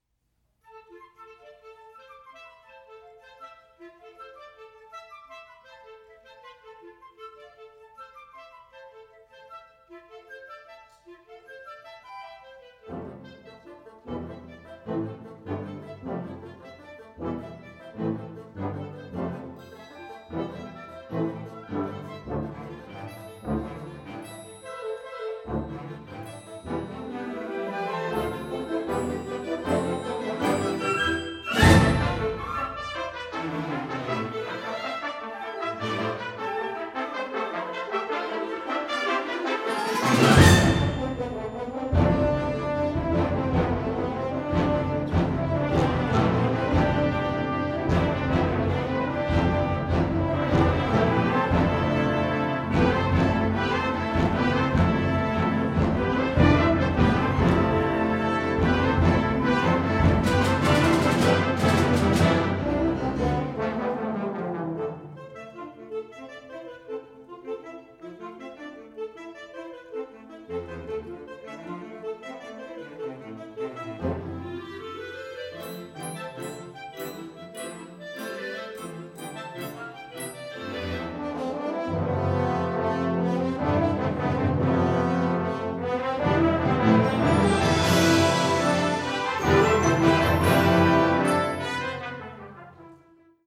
Unterkategorie Ouvertüre (Originalkomposition)
Besetzung Ha (Blasorchester)